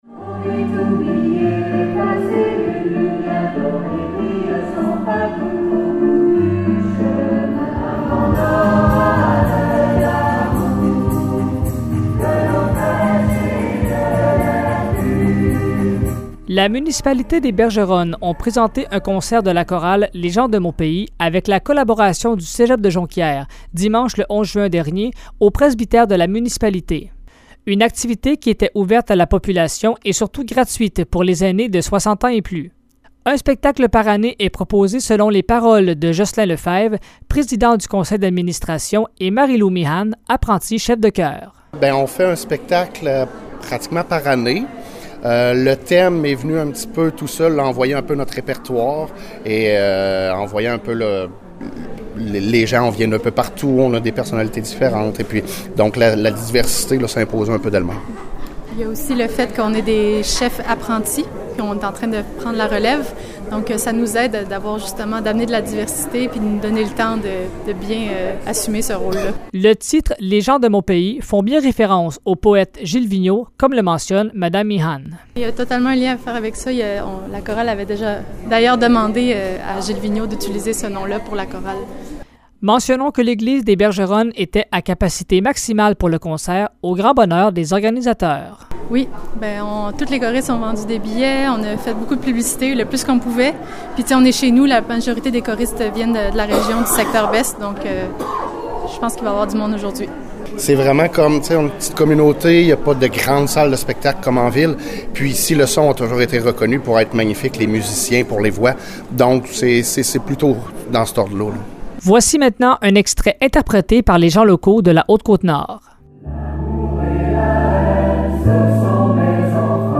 La chorale «Les gens de mon pays» a offert un concert à la population, le 11 juin dernier, au presbytère des Bergeronnes.